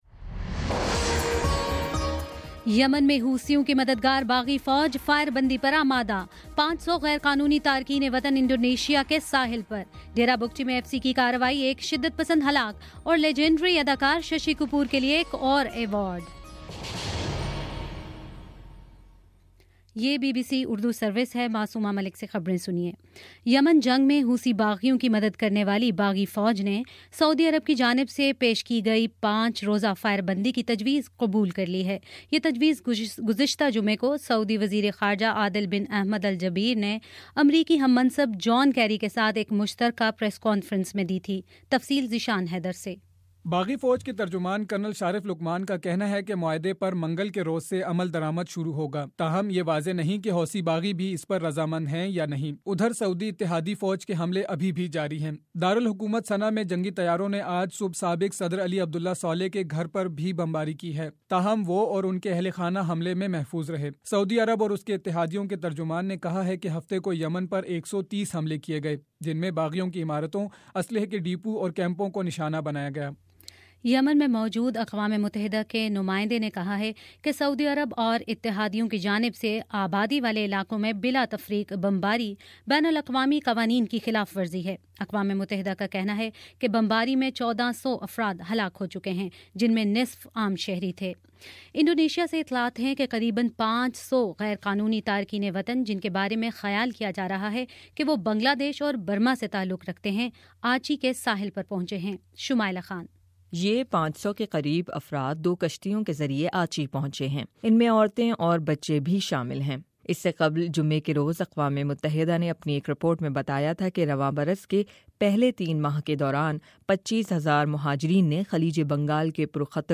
مئی10 : شام پانچ بجے کا نیوز بُلیٹن